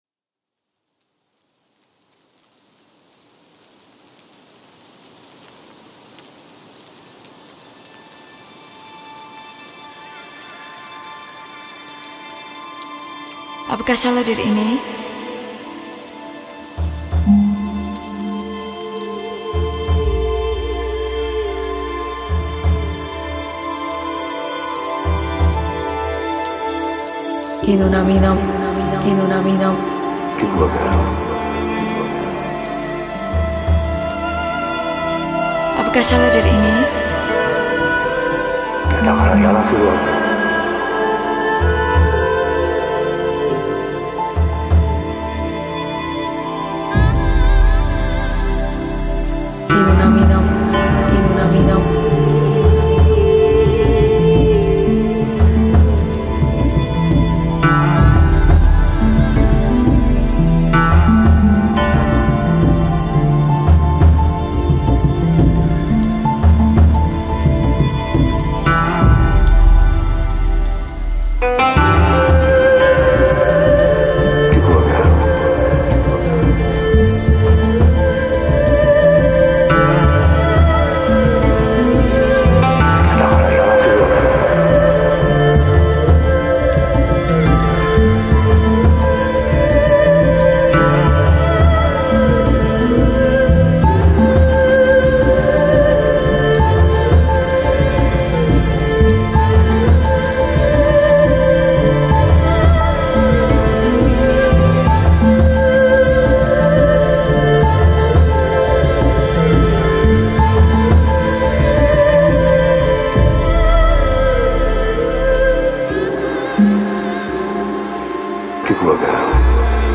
Жанр: Organic House, Downtempo